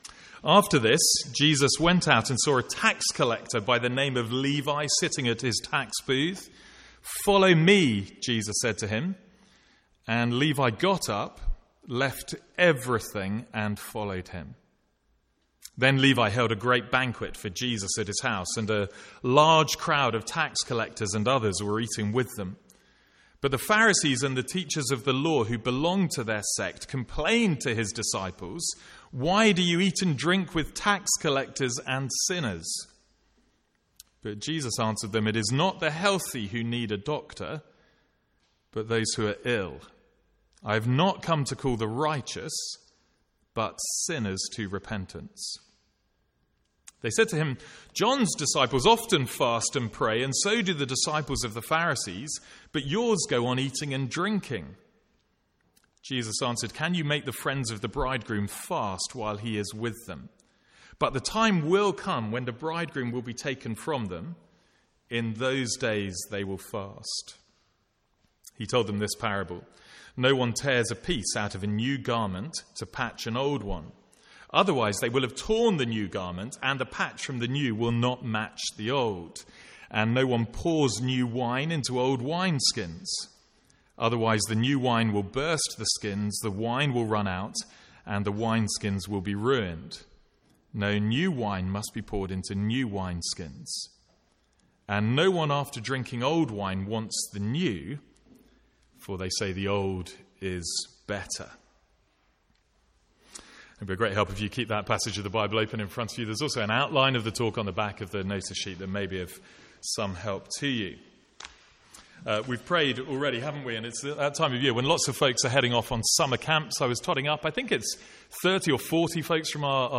Sermons | St Andrews Free Church
From the Sunday morning series in Luke.